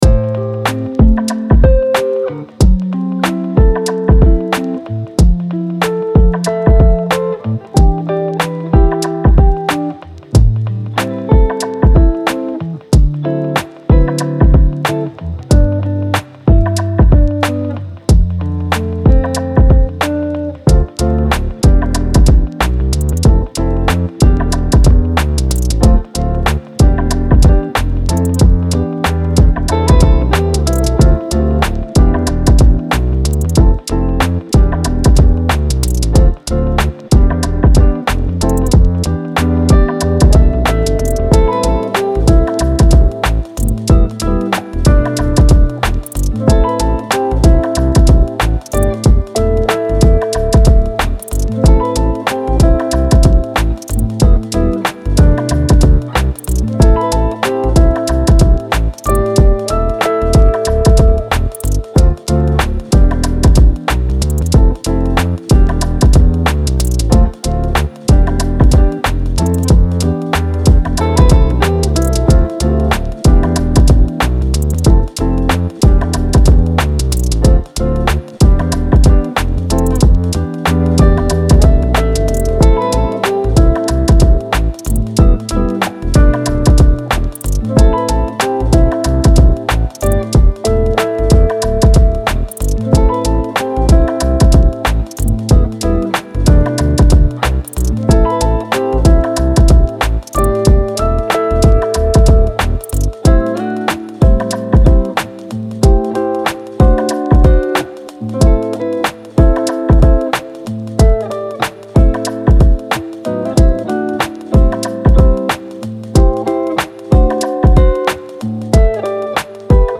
24 Tracks 60 Minutes Instrumentals made on laptop